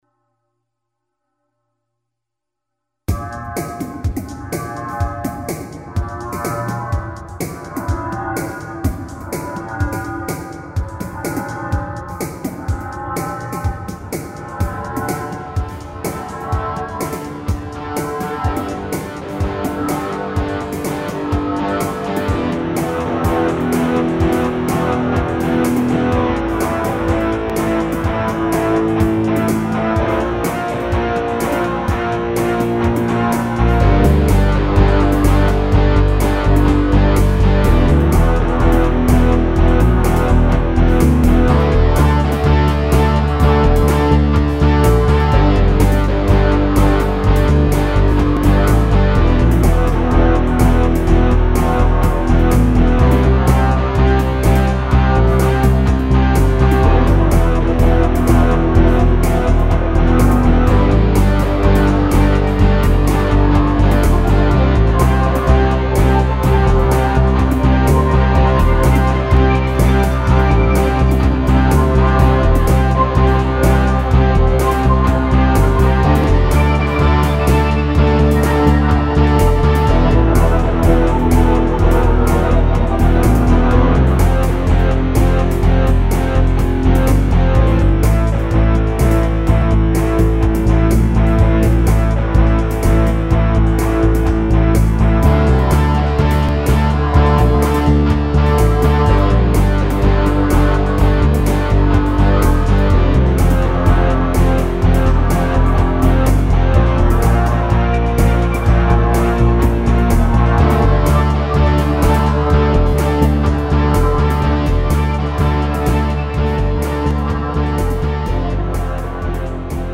Hopalong (2.51) Mi (E) -  125
Plugins :  Font12! , Evm Bass Line , MDA EPiano , Synth1 ,
Drum loop : Arythm
Mode : Locrien